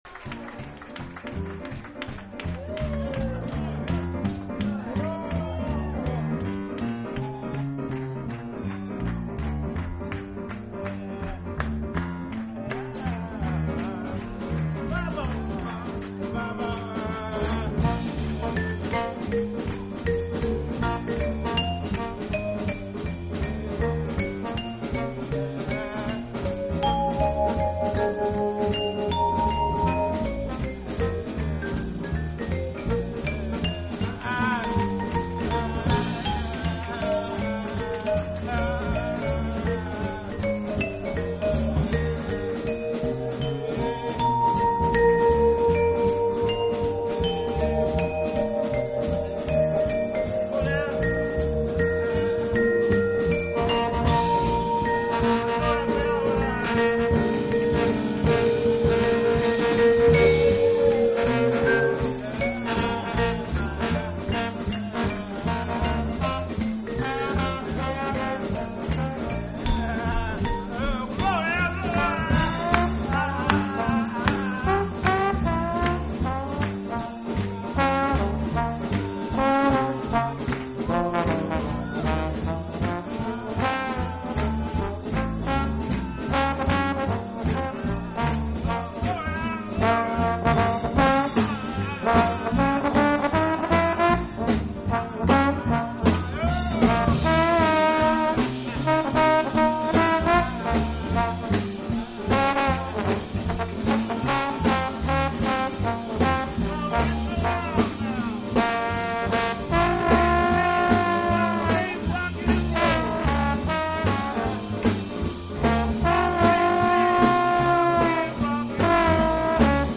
音樂類別 ： 爵士樂 ． 爵士大樂團